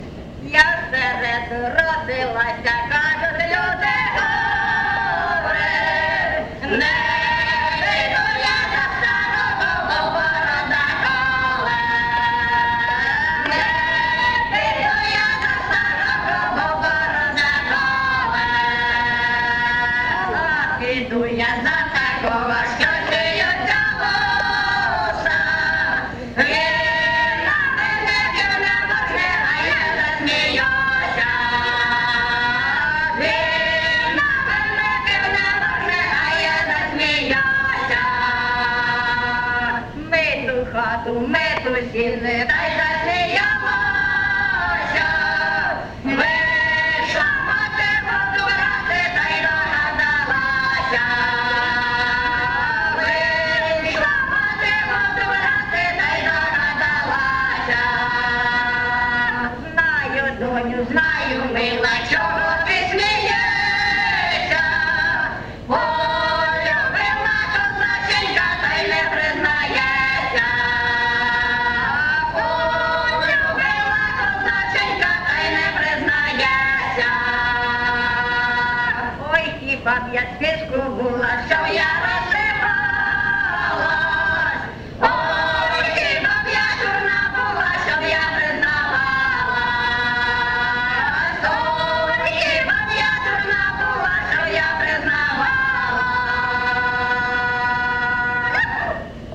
ЖанрЖартівливі
Місце записус. Очеретове, Валківський район, Харківська обл., Україна, Слобожанщина